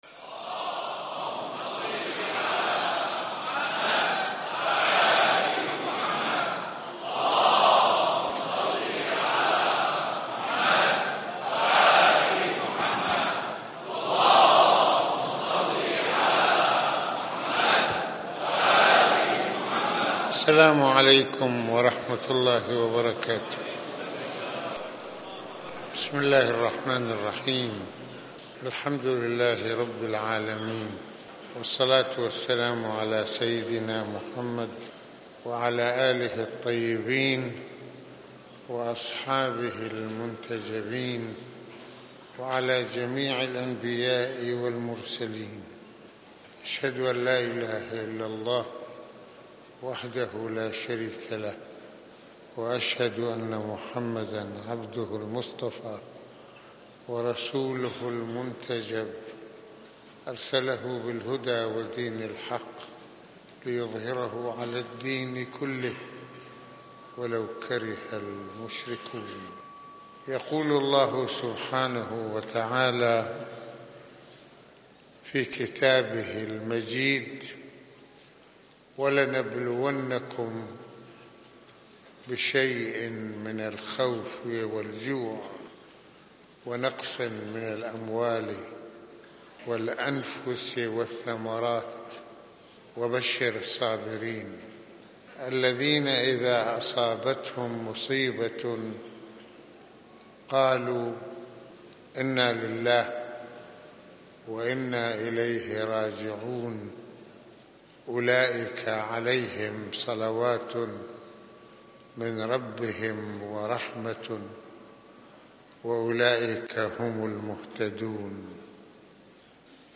- المناسبة : خطبة الجمعة المكان : مسجد الإمامين الحسنين (ع) المدة : 29د | 02ث المواضيع : مفهوم الصّبر وقيمته في الإسلام - الصّبر أمام البلاء - معنى البلاء - صبر رسول الله في مواجهة التحدّيات - ملازمة الصّبر للإيمان.